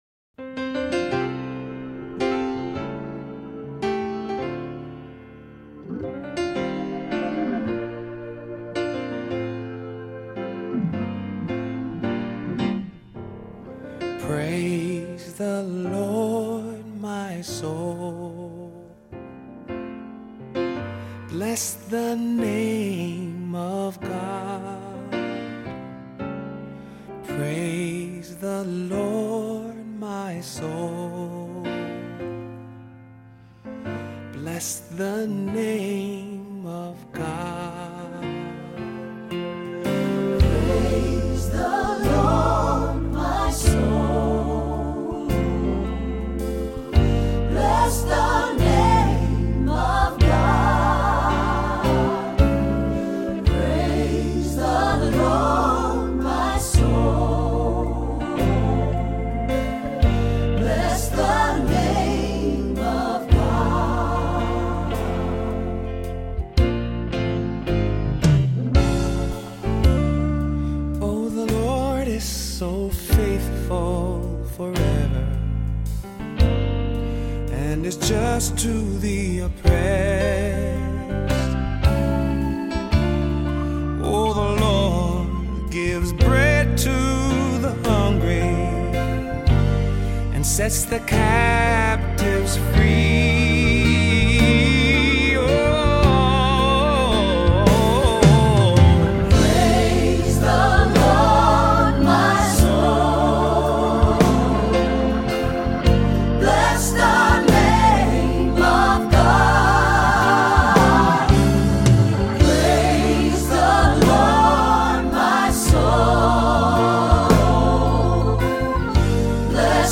Voicing: Assembly, cantor,SATB